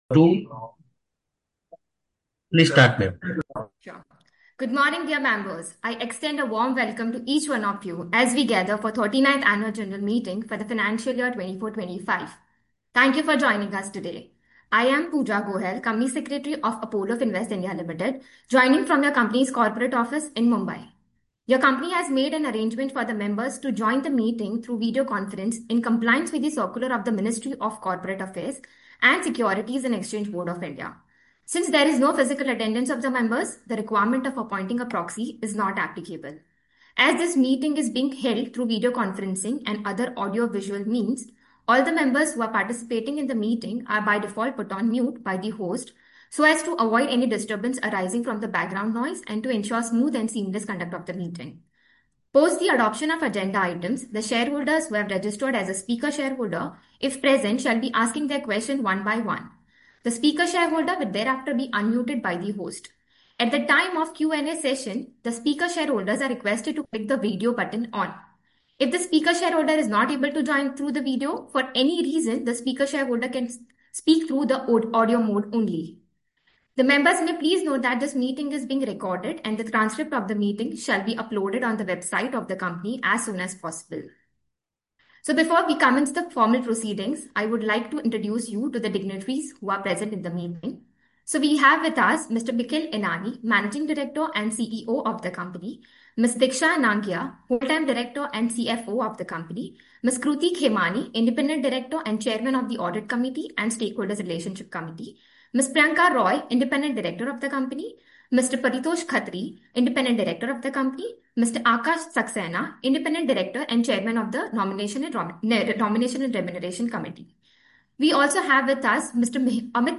Audio Recording of 39th AGM of the Company
Apollo+Finvest+AGM+Sept+18.mp3